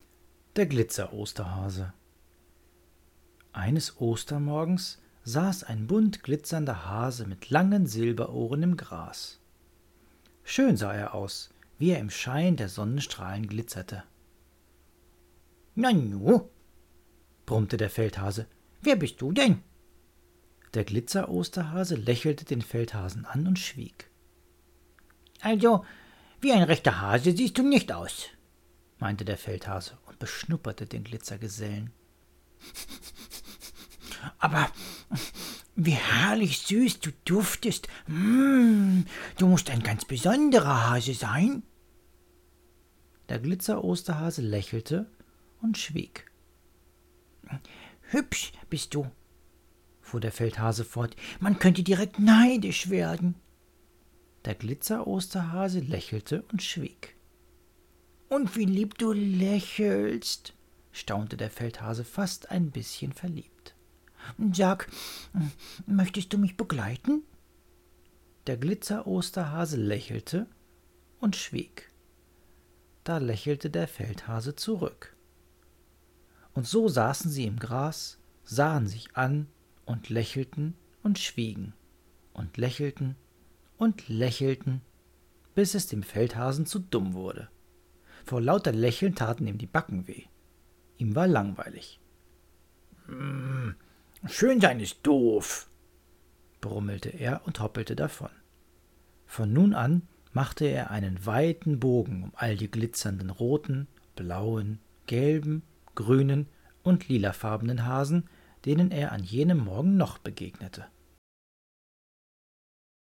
Hier erzählt dir